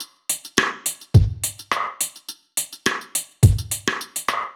Index of /musicradar/dub-drums-samples/105bpm
Db_DrumKitC_Dry_105-03.wav